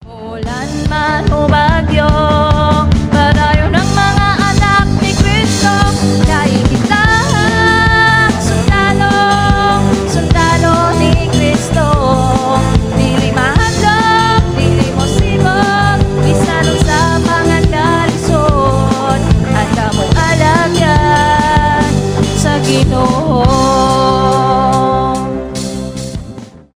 pop rock , live
христианские